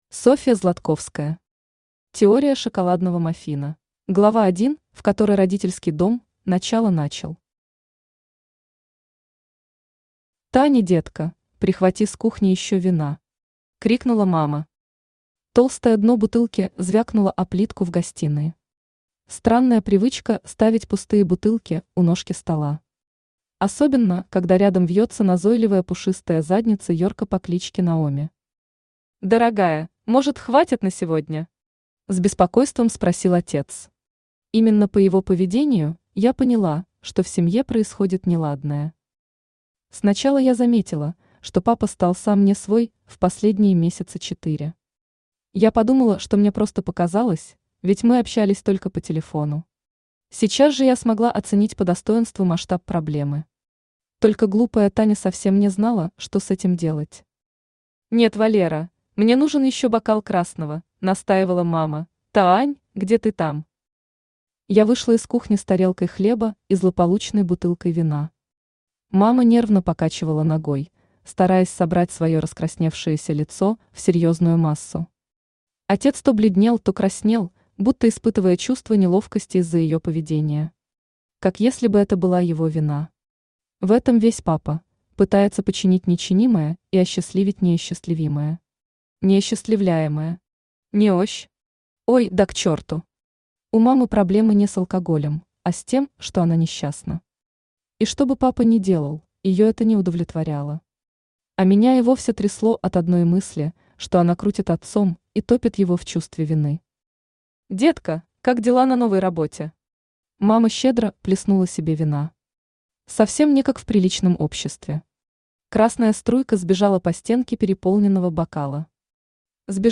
Аудиокнига Теория шоколадного маффина | Библиотека аудиокниг
Aудиокнига Теория шоколадного маффина Автор Софья Златковская Читает аудиокнигу Авточтец ЛитРес.